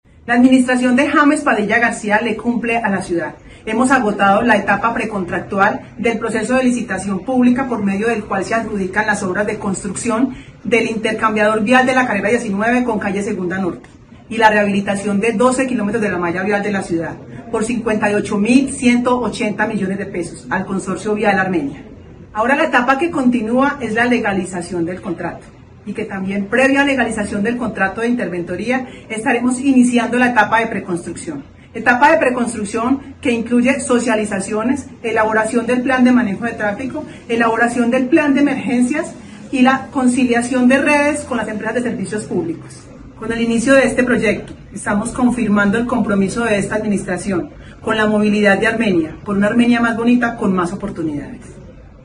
Claudia Arenas, secretaria de infraestructura de Armenia